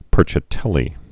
(pûrchə-tĕlē, pĕrchä-tĕllē)